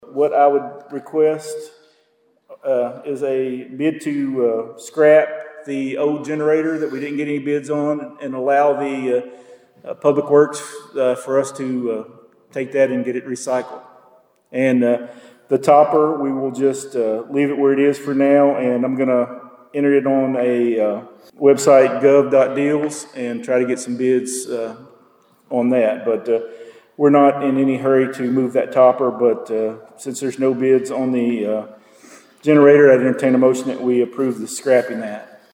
The Princeton City Council received an update on a proposed street acceptance request and approved a one-year extension for a burial site preparation contract at Monday night’s meeting.